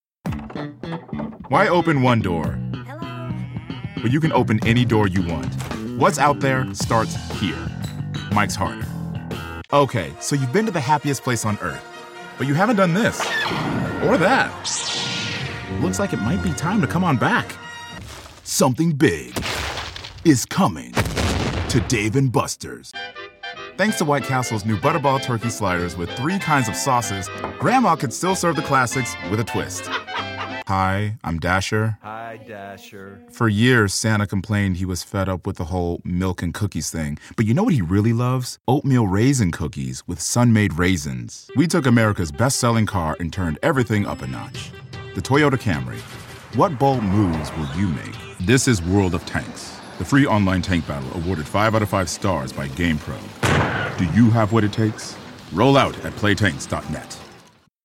African American , Announcer , Male , Mid-Range , Versatile